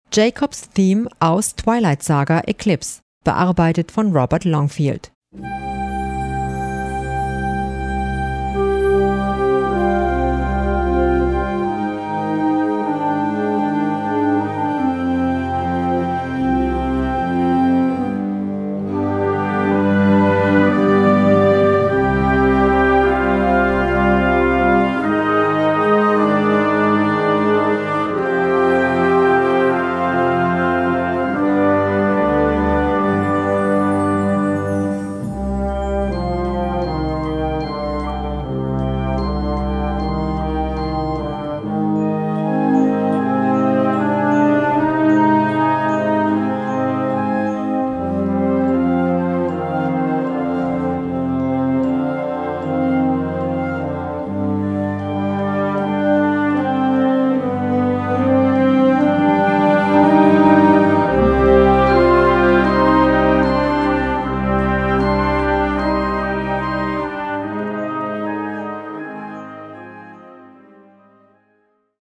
Besetzung: Blasorchester
eindringliche Melodie
für Blasorchester